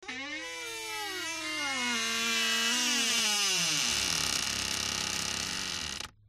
woodendoor.mp3